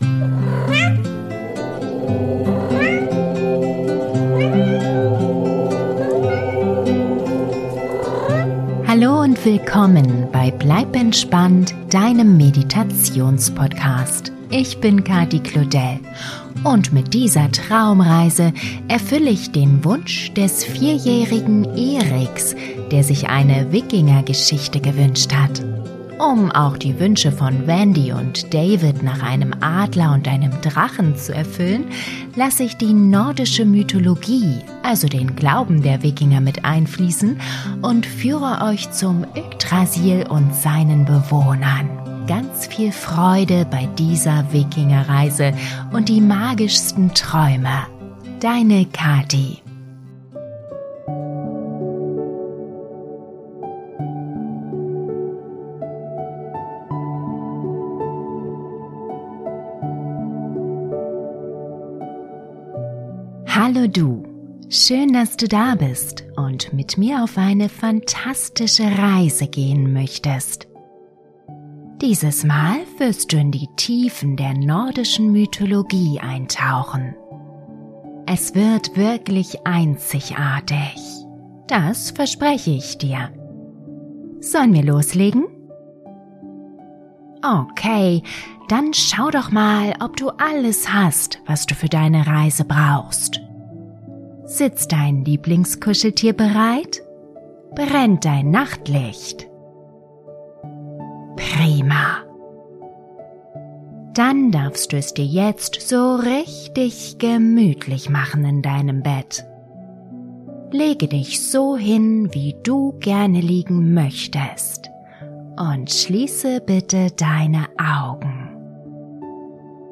Wikinger Traumreise für Kinder, Jugendliche & Erwachsene - Das Geheimnis des Weltenbaums - Geschichte über Wikinger ~ Bleib entspannt! Der Meditations-Podcast - magische Momente für Kinder & Eltern Podcast